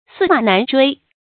注音：ㄙㄧˋ ㄇㄚˇ ㄣㄢˊ ㄓㄨㄟ
駟馬難追的讀法